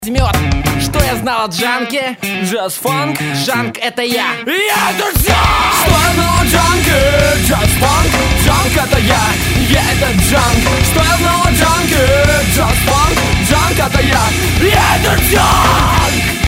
Категория: Рок-музыка